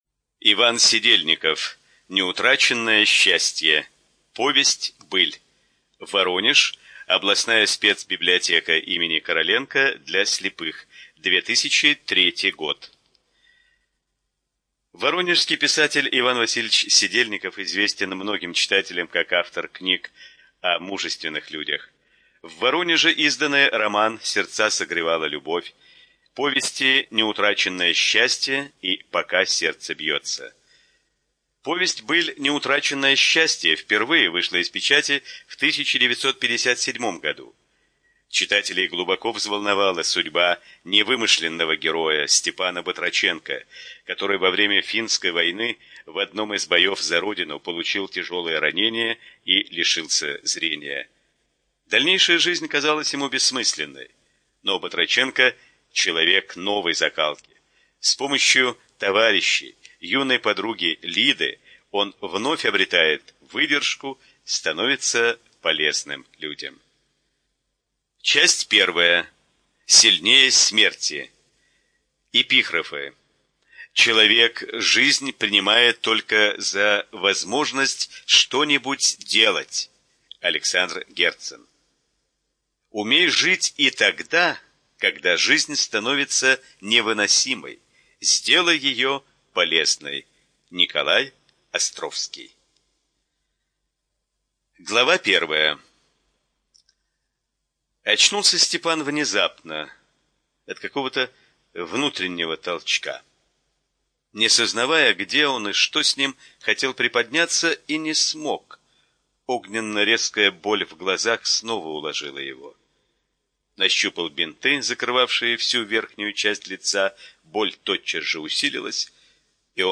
Студия звукозаписиВоронежская областная библиотека для слепых имени Короленко